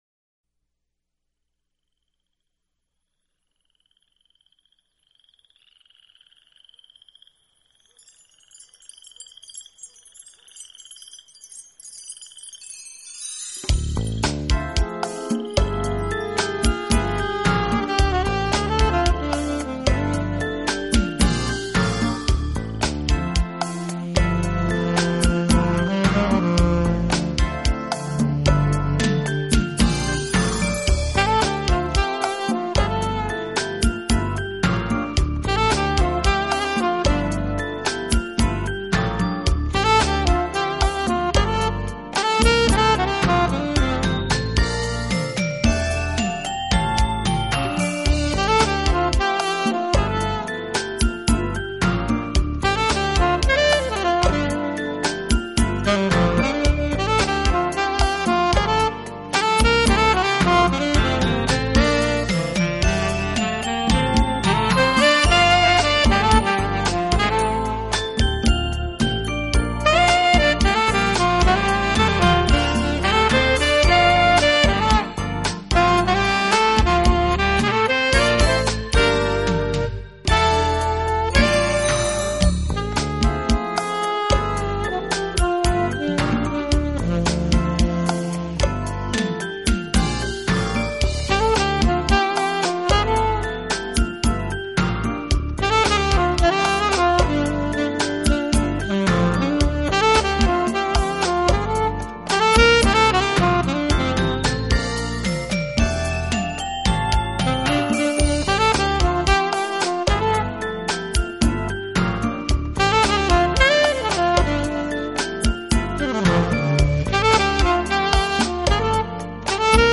Genre: Smooth Jazz / Crossover Jazz